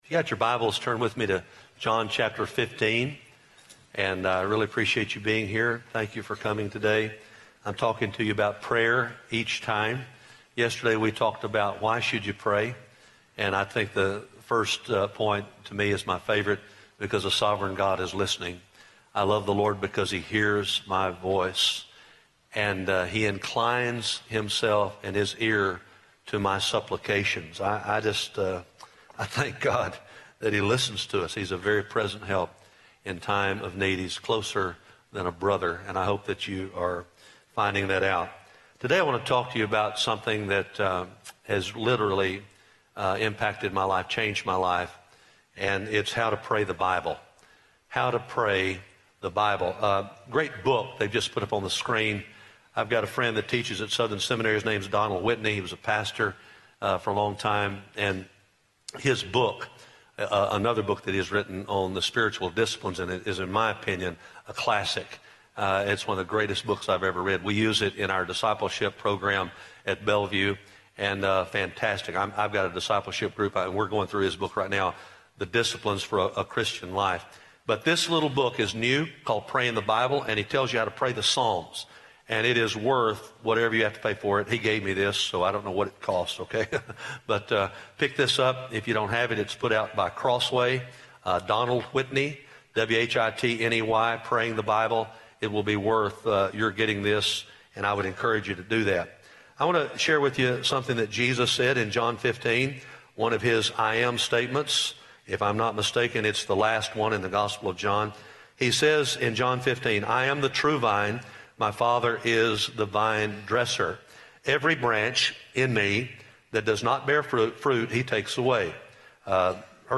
Faith in Practice Chapel
Address: "How to Pray the Bible" from John 15:1-8 Recording Date: Oct 15, 2015, 10:00 a.m. Length: 33:05 Format(s): MP3 ; Listen Now Chapels Podcast Subscribe via XML